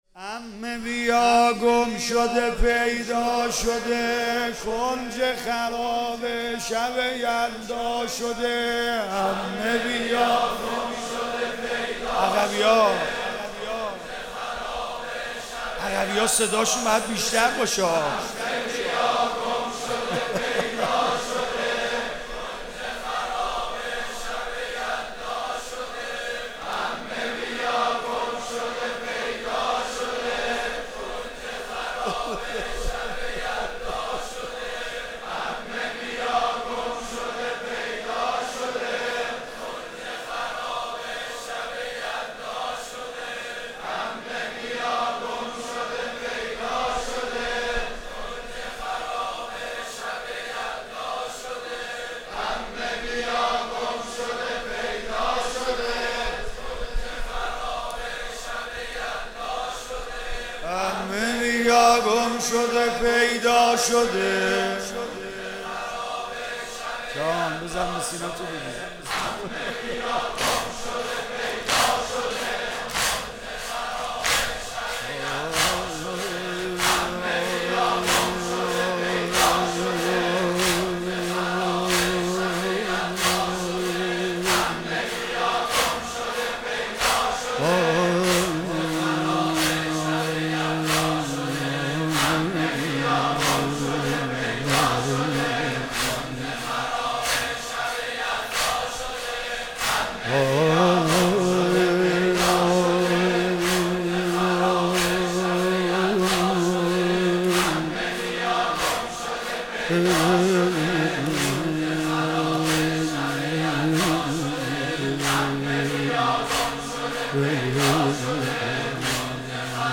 در آستان مقدس شاه میرحمزه علیه السلام اصفهان برگزار شد.